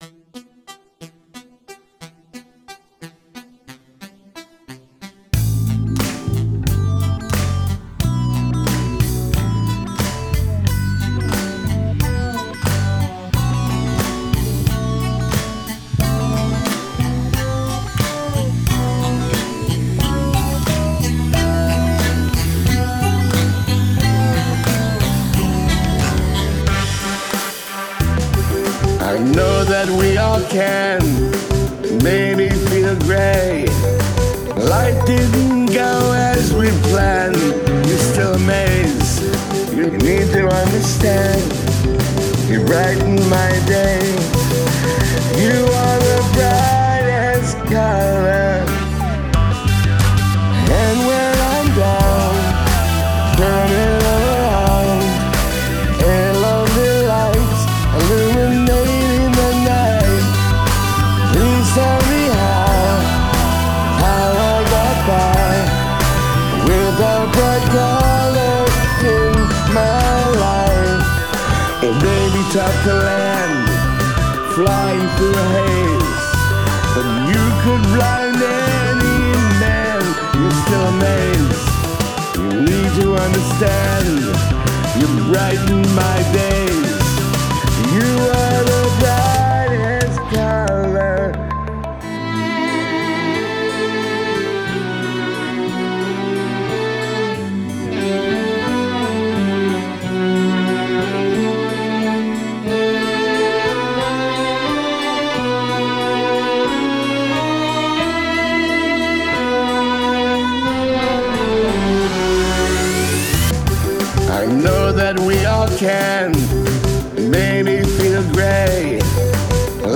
keys and producer
bass and strings
melody and vocals). A positive pop song.